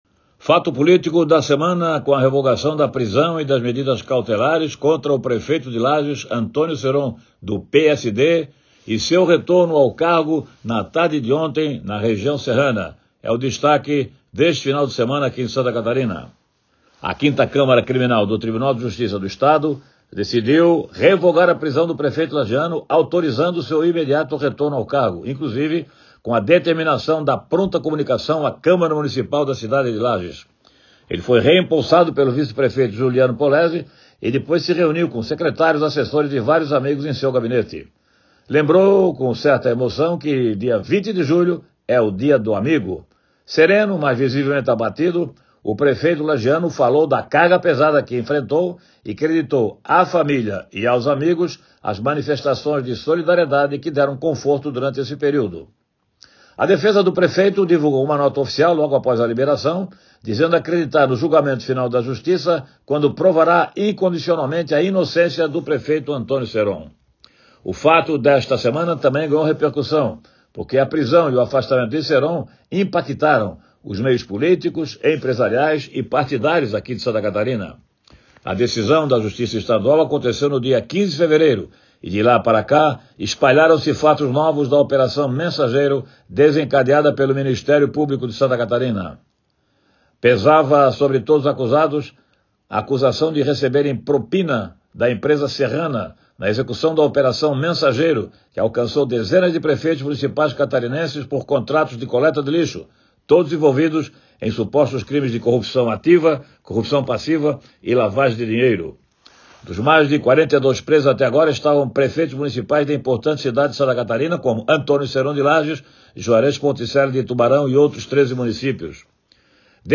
Jornalista destaca a grande repercussão no cenário político estadual após a justiça soltar Antônio Ceron e o ex-secretário de Lages, que foram presos na Operação Mensageiro